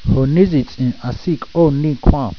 Hoh neesee tts-ehn ahsee-eh kk-ohnee koo-ehn